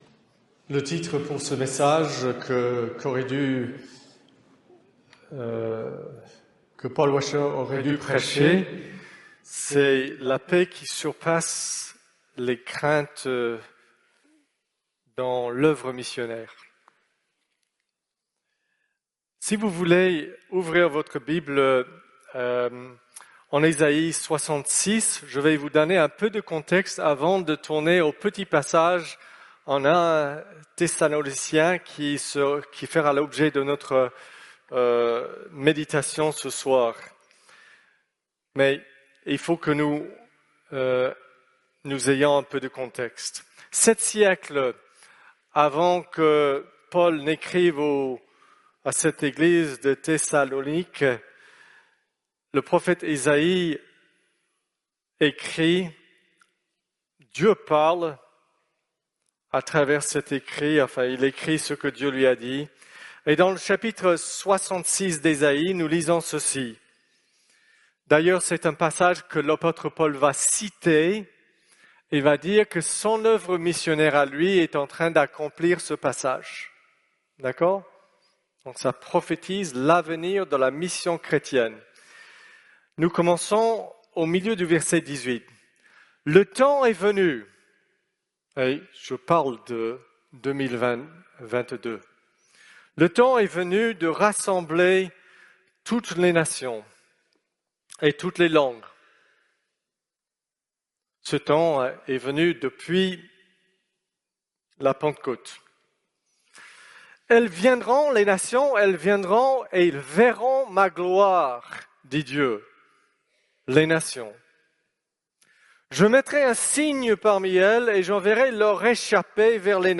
6ème Conférence Bonne Nouvelle | 22 et 23 avril 2022, Paris.